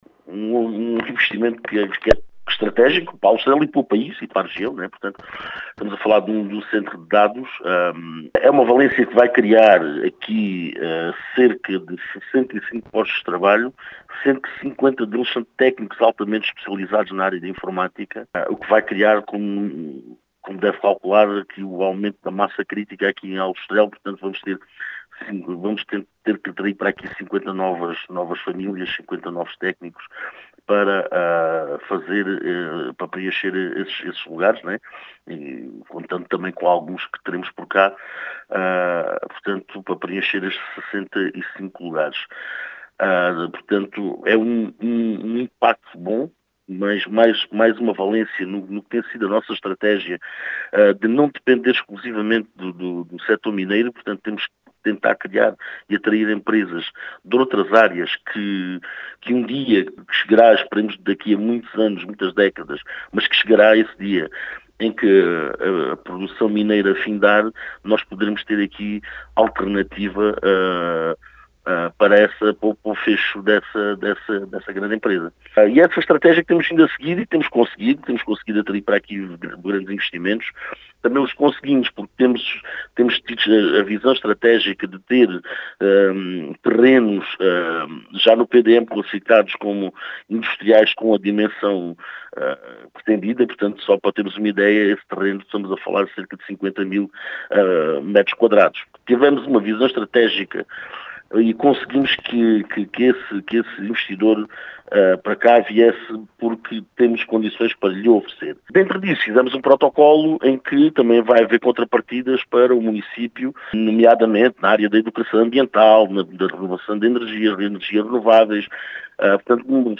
As explicações são de Carlos Teles, presidente da Câmara Municipal de Aljustrel, que realça a importância deste investimento.